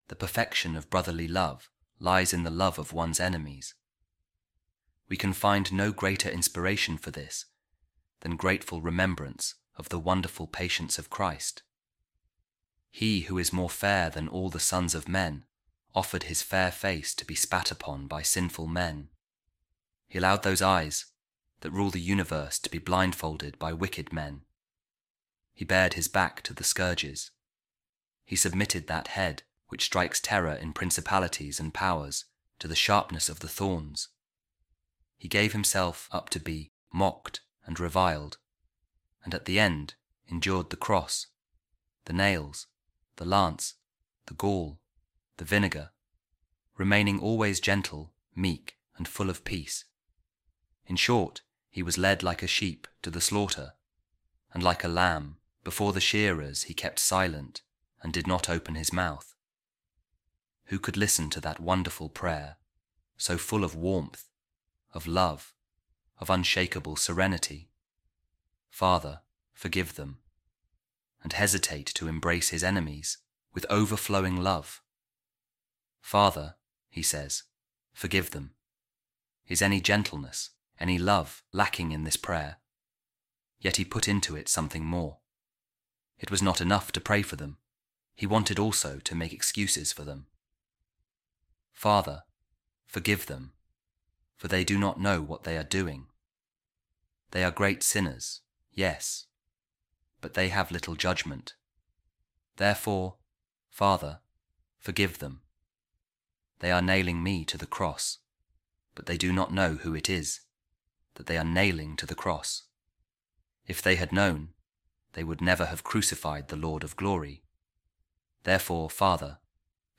Divine Office | Office Of Readings